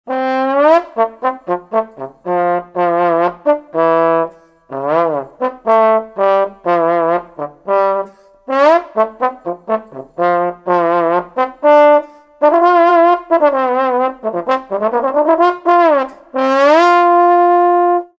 TTBNSOLO.mp3